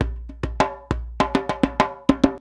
100DJEMB18.wav